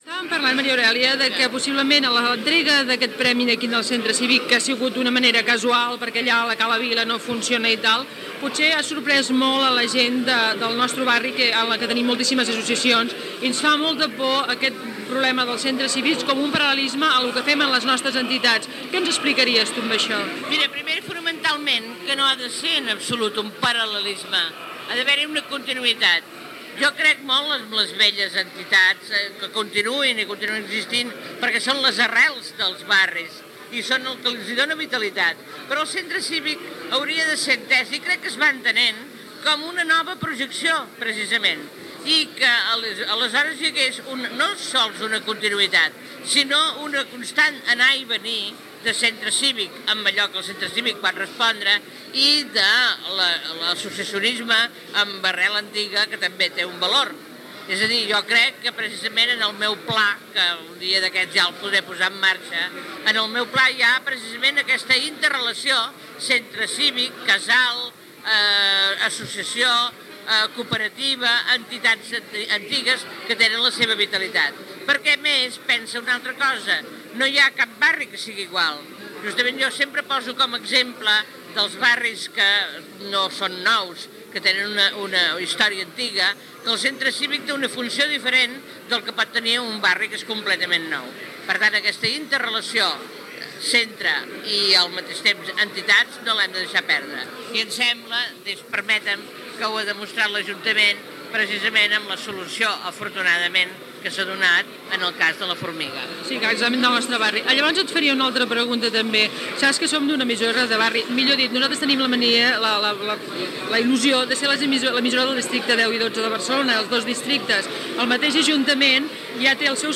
Entrevista a l'escriptora Maria Aurèlia Capmany, regidora de cultura de l'Ajuntament de Barcelona, sobre la funció dels nous centres cívics municipals i el paper de les ràdios de barri
Entrevista feta el dia del lliurament de la 21ena edició del Premi Sant Martí, que es va fer l’any 1983.